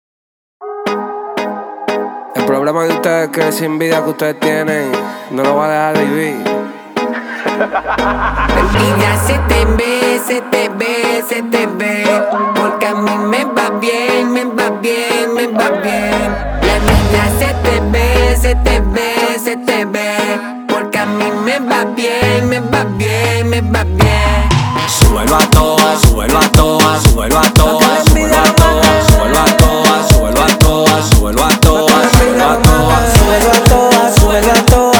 Жанр: Латино